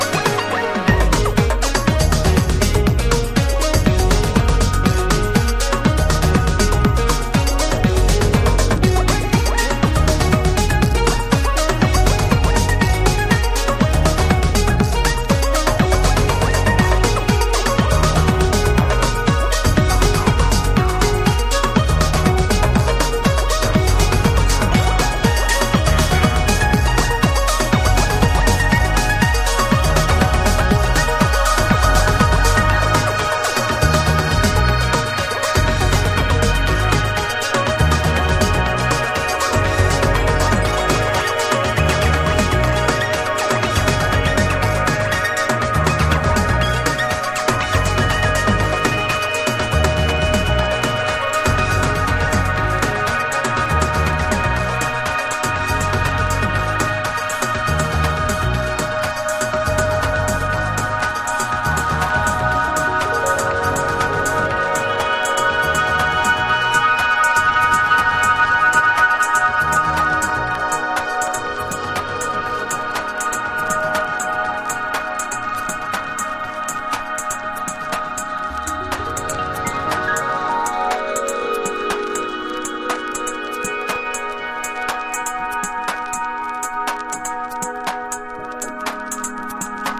ロウなリズムと北欧らしい旋律が◎な陶酔系エレクトロハウス！
ELECTRO HOUSE / TECH HOUSE